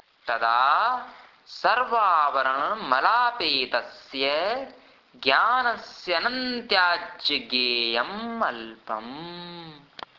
Chant  कूर्मनाड्यां स्थैर्यम् ।।